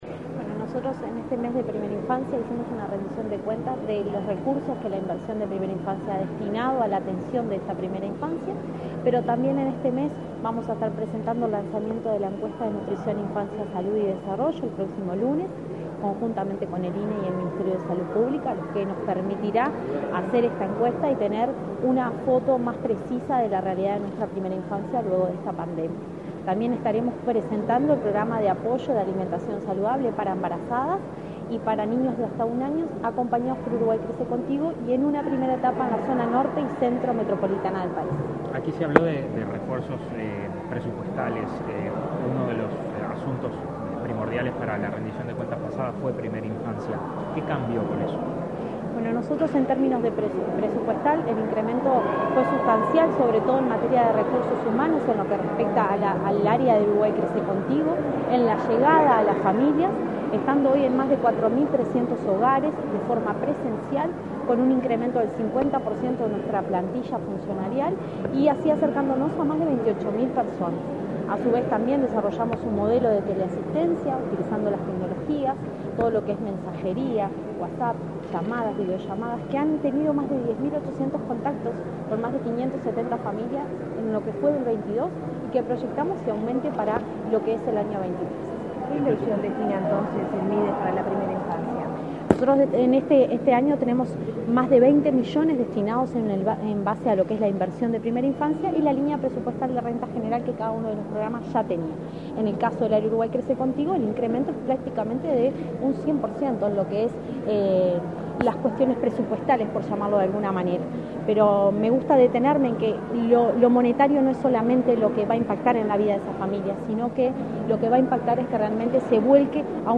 Declaraciones a la prensa de la directora Nacional de Desarrollo Social del Mides, Cecilia Sena
Declaraciones a la prensa de la directora Nacional de Desarrollo Social del Mides, Cecilia Sena 03/05/2023 Compartir Facebook X Copiar enlace WhatsApp LinkedIn Tras participar en el lanzamiento del Mes de la Primera Infancia, este 3 de mayo, la directora Nacional de Desarrollo Social del Ministerio de Desarrollo Social (Mides), Cecilia Sena, realizó declaraciones a la prensa.